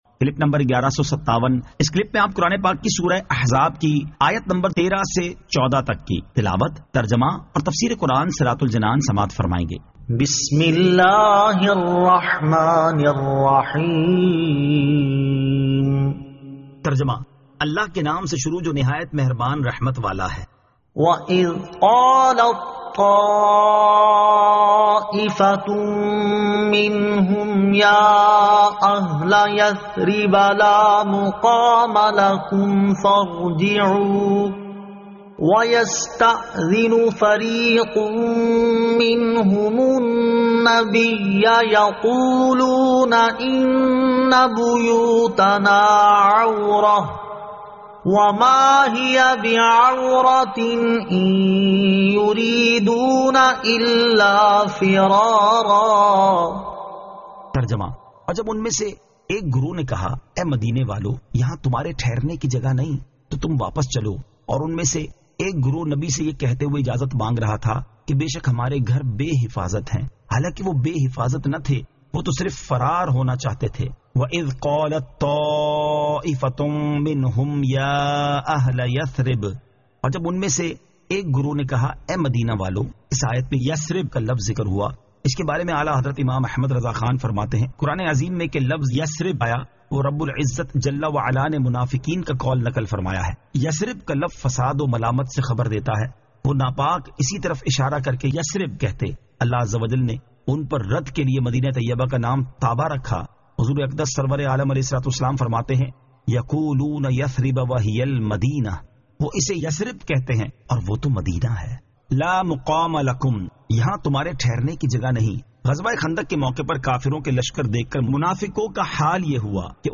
Surah Al-Ahzab 13 To 14 Tilawat , Tarjama , Tafseer